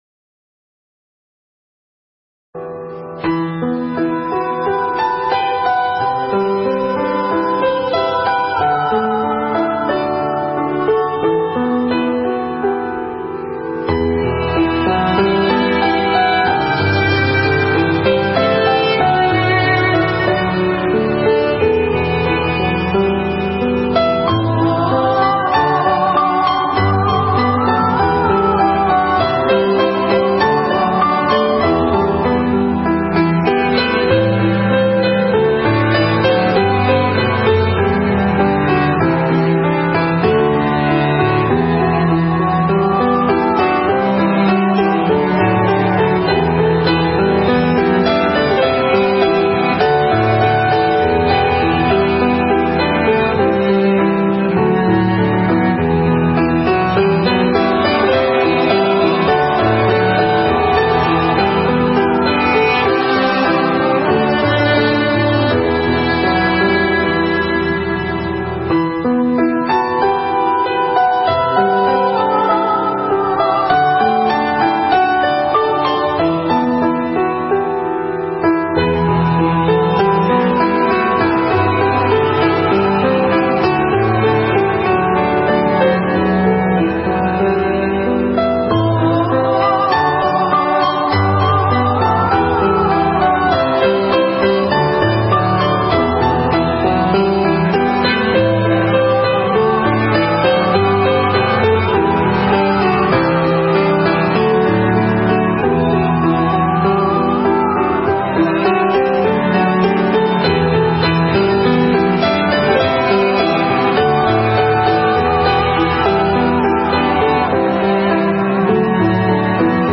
Mp3 Pháp Thoại Hoan Hỷ Đắc Thiền Buồn Phiền Sanh Bệnh 1
giảng tại Vu Viện Tường Vân trong Khóa Tu An Lạc Lần Thứ 21